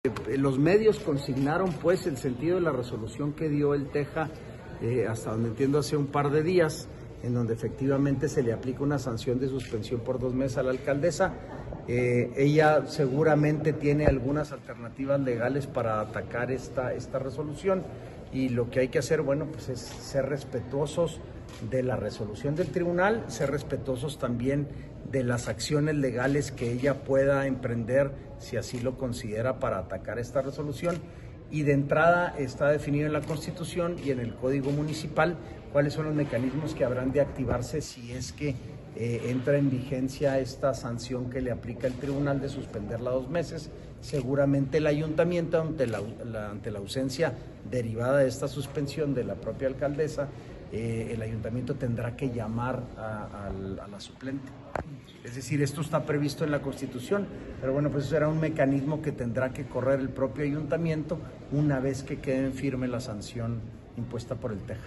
AUDIO: SANTIAGO DE LA PEÑA, SECRETARIO GENERAL DE GOBIERNO (SGG)